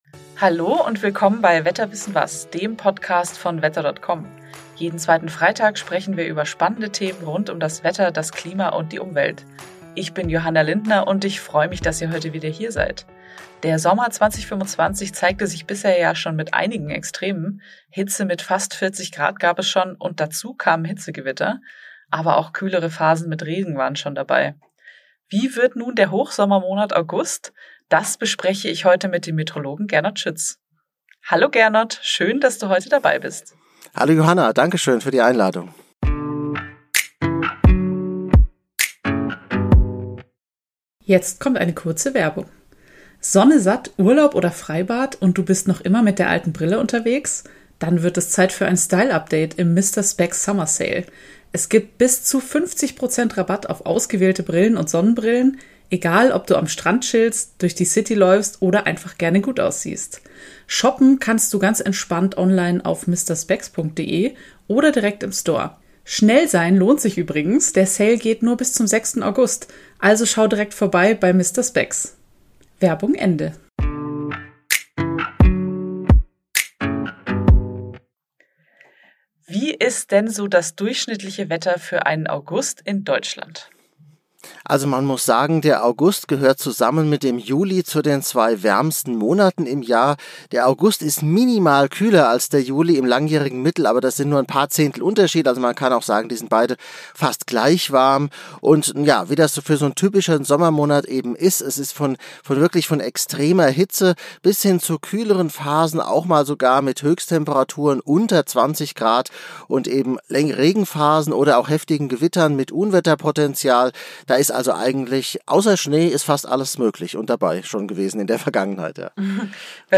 sprechen mit Expert:innen und Meteorolog:innen über spannende Themen in den Bereichen Wetter, Klima und Co.